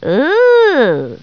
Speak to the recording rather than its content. I Love Lucy TV Show Sound Bites